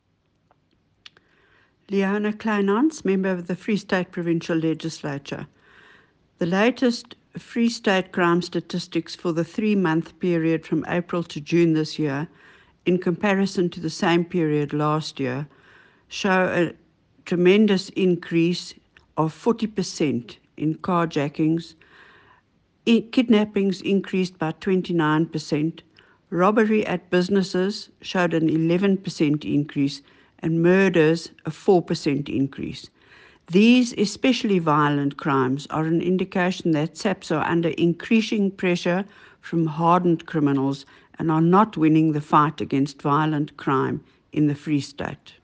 Afrikaans soundbites by Leona Kleynhans MPL